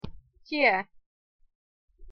Произносим твердо, это слово -исключение из правил чтения.
pronunciation_sk_tie.mp3